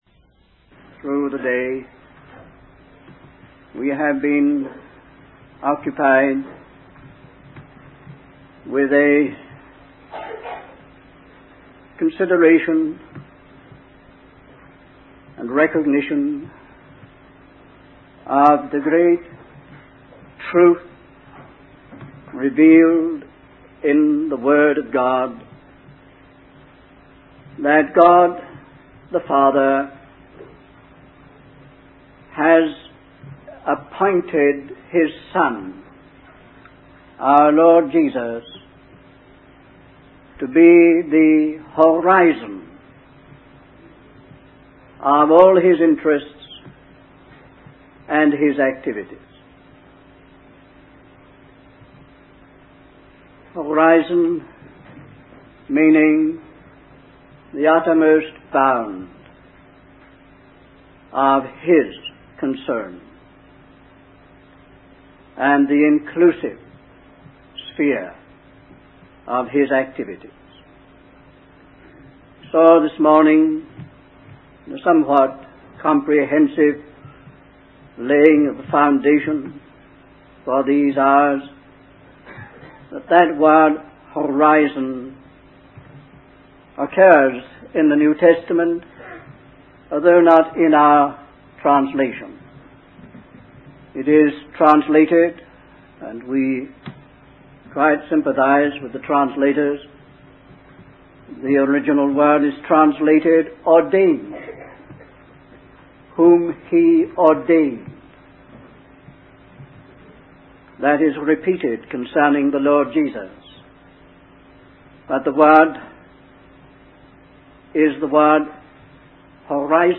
In this sermon, the speaker explores the concept of Christ being the ultimate purpose and representative of God's creation. The sermon begins by discussing how God chose Abraham to be the first of a people who would receive His blessings.